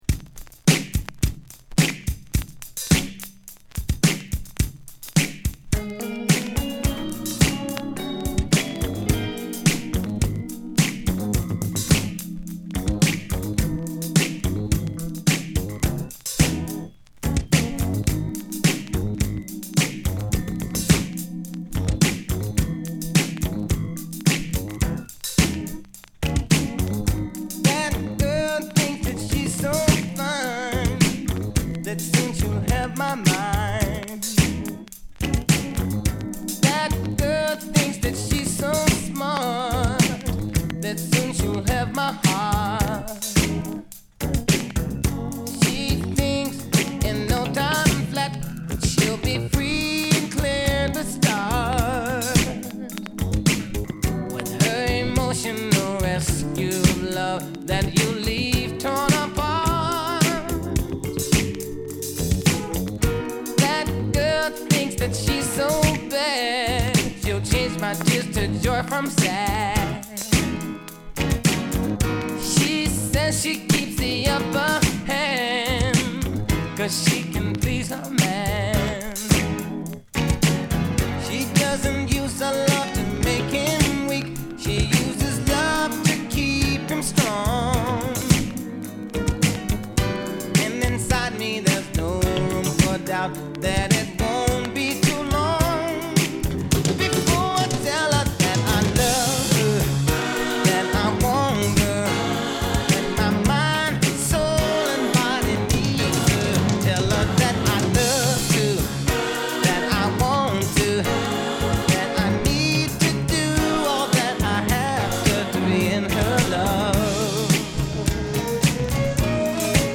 ナイスなドラムブレイクから始まるアーバンソウル